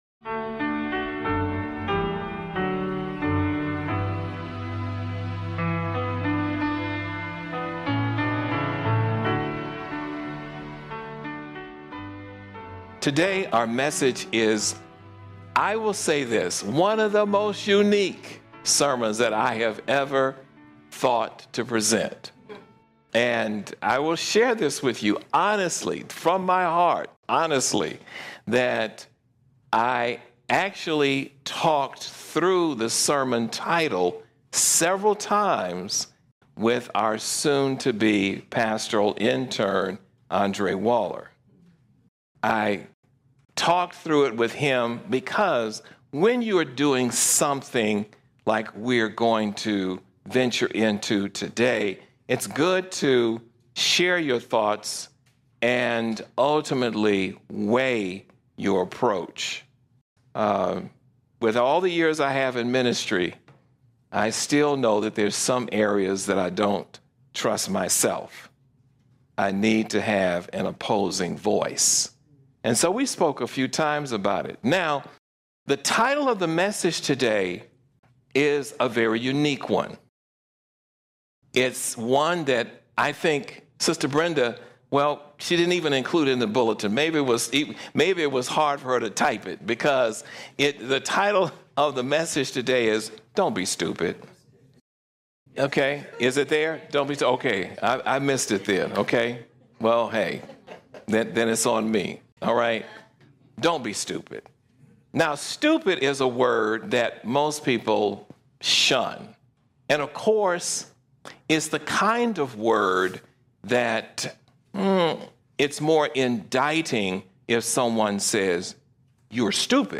This sermon unpacks powerful lessons from Scripture—showing that true faith is a confident trust in God’s promises, not blind belief, and that human pride and kingdoms always fall under His sovereignty. With vivid biblical warnings against superficial faith, it calls believers to Spirit-led transformation, courageous loyalty to God, and daily surrender to His will.